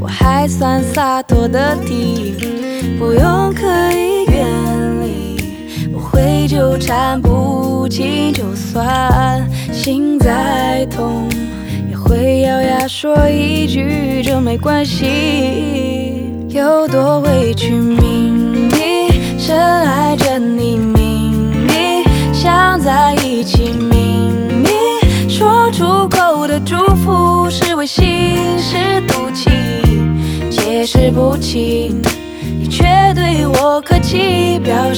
Скачать припев, мелодию нарезки
Mandopop Pop
2024-08-09 Жанр: Поп музыка Длительность